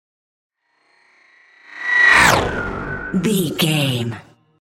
Sci fi vehicle whoosh
Sound Effects
dark
futuristic
whoosh